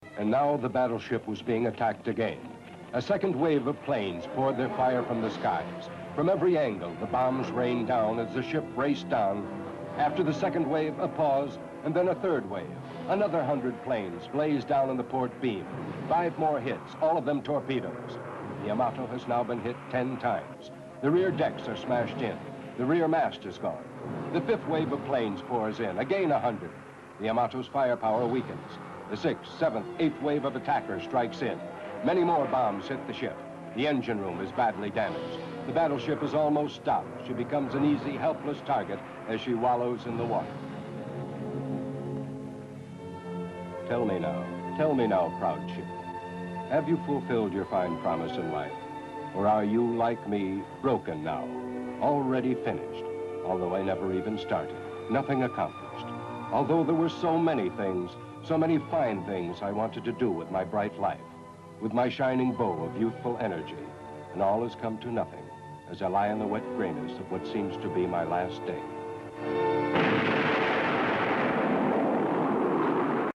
A long stretch of narration in the Axis International dub of Battle of Okinawa (1971)